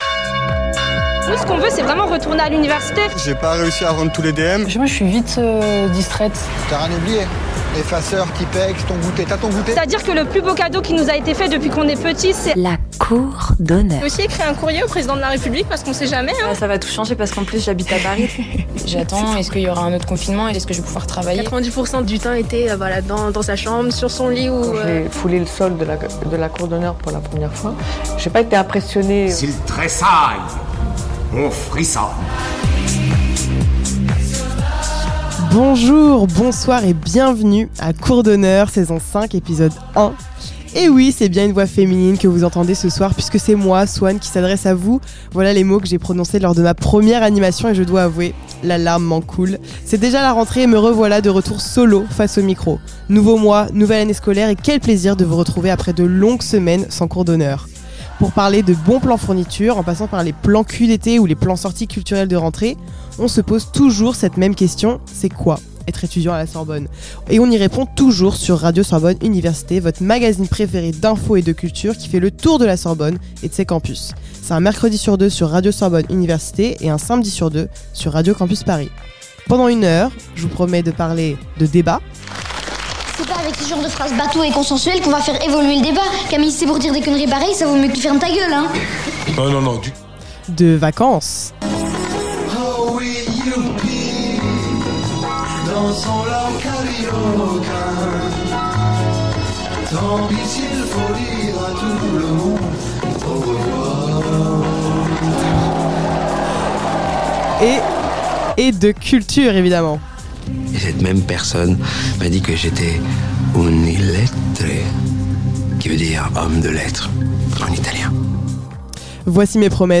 Un mercredi sur deux sur Radio Sorbonne Université
Type Magazine Culture